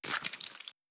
Flesh Hits